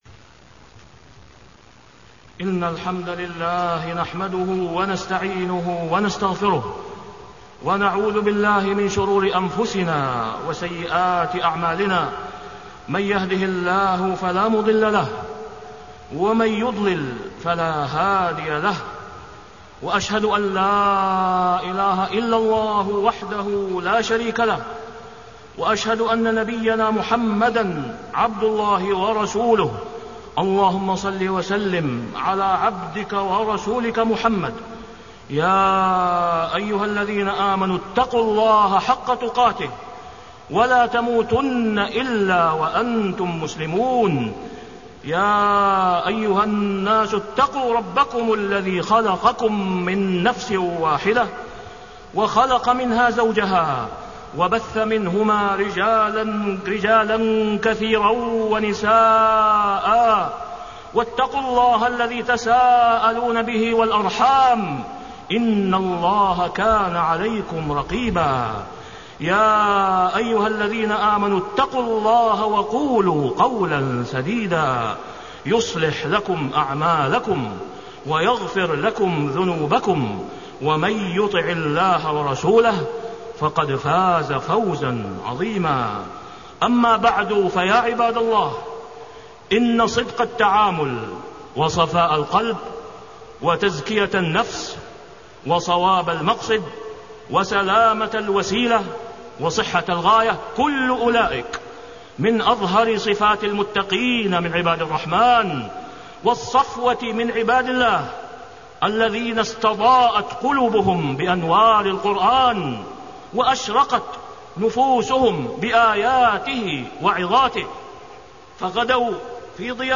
تاريخ النشر ٢٧ رجب ١٤٢٨ هـ المكان: المسجد الحرام الشيخ: فضيلة الشيخ د. أسامة بن عبدالله خياط فضيلة الشيخ د. أسامة بن عبدالله خياط ولا يحيق المكر السئ إلا بأهله